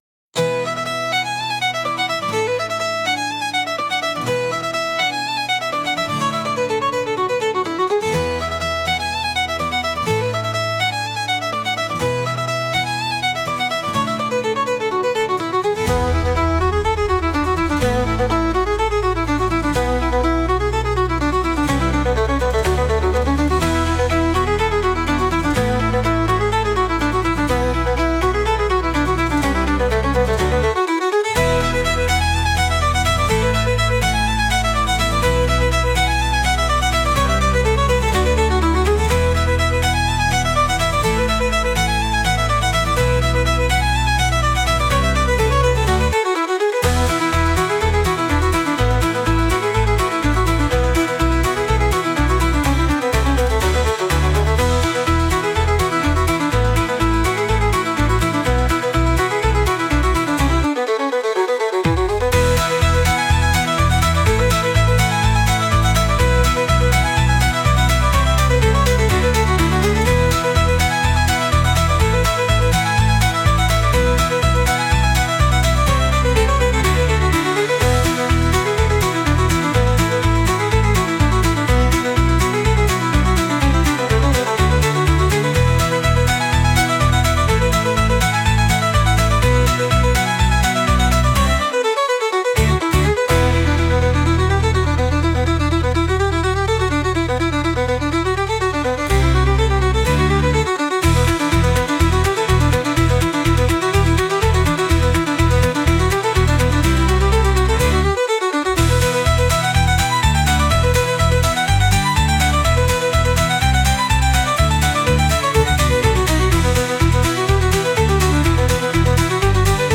お祭りの喧騒と楽しさを表したようなケルト音楽です。